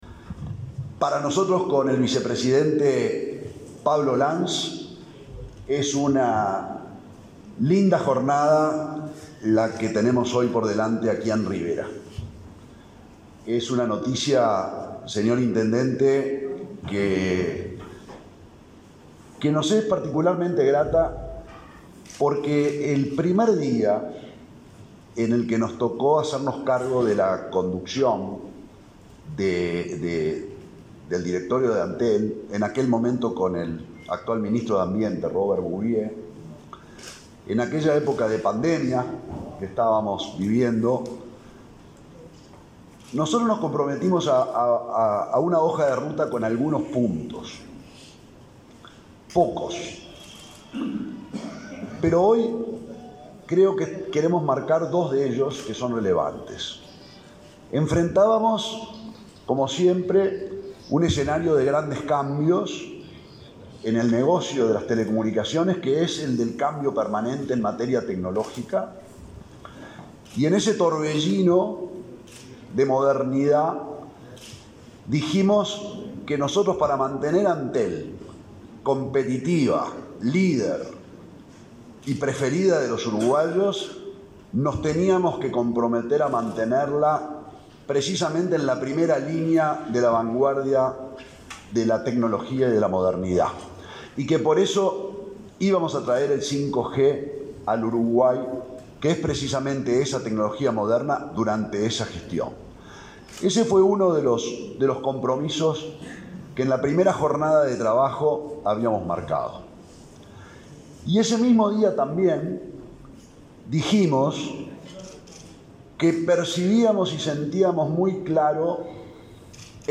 Palabras del presidente de Antel, Gabriel Gurméndez
El presidente de Antel, Gabriel Gurméndez, presentó en el departamento de Rivera la tecnología de quinta generación (5G).